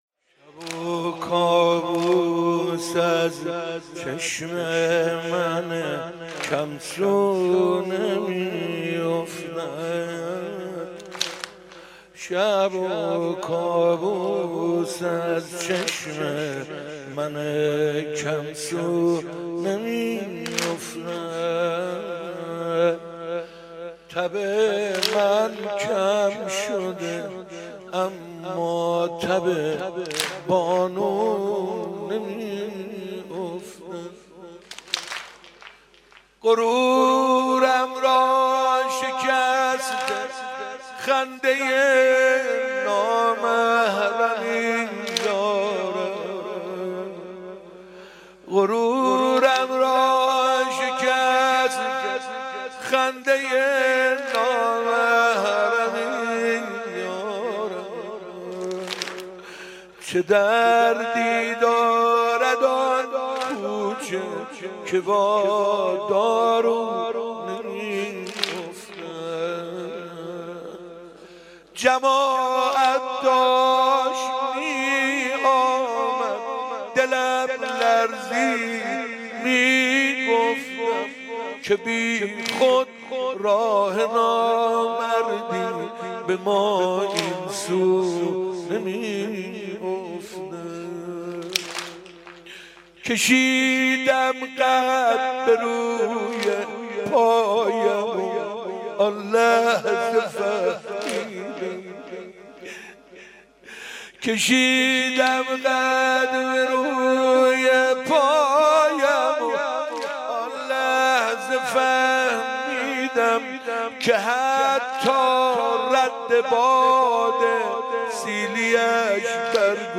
1 بهمن 97 - امامزاده عبدالله - واحد - کابوس از چشم من کم سو نمی افتد
حاج حسين سازور واحد